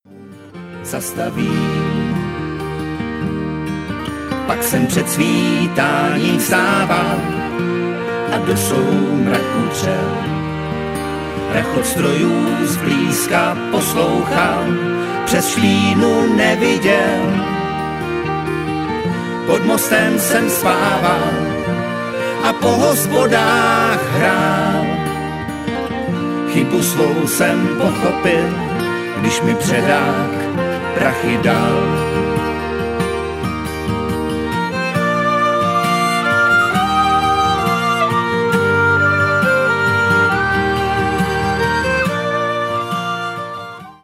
Irská tradiční hudba s folkovými prvky
Nahráno a smícháno: 2016 ve studiu Klíč